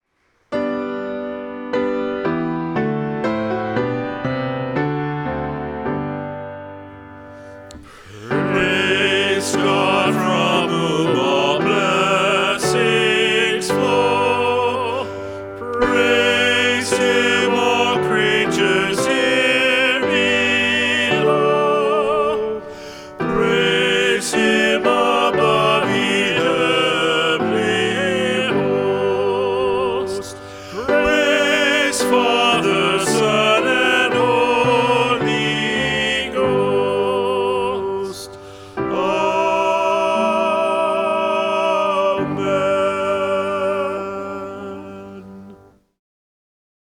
Service of Worship
Doxology